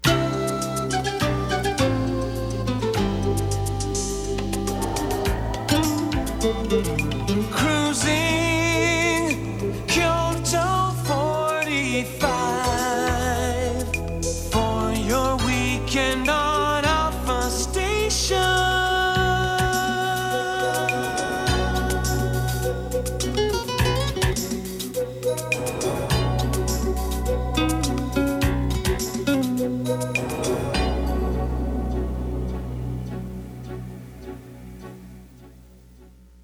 音源は全てステレオ録音です。
全体を通しての感想ですが、ジャズを基調としたとても贅沢な楽曲となっています。